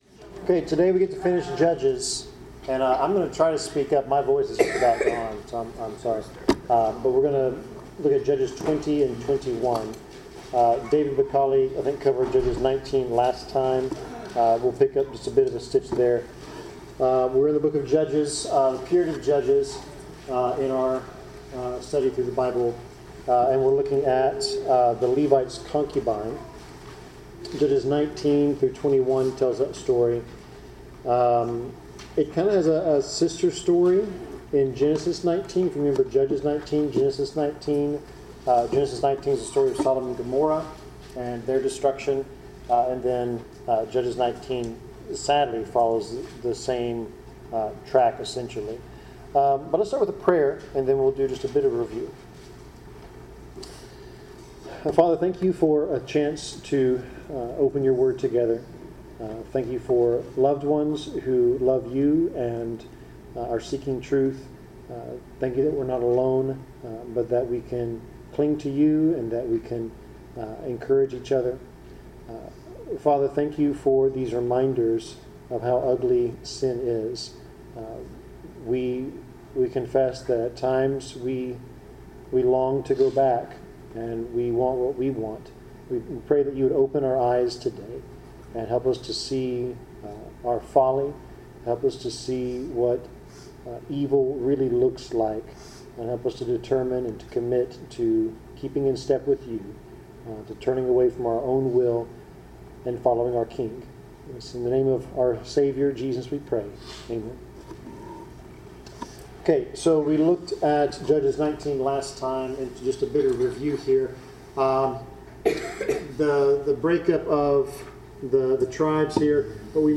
Bible class: Judges 19-21
Passage: Judges 20-21 Service Type: Bible Class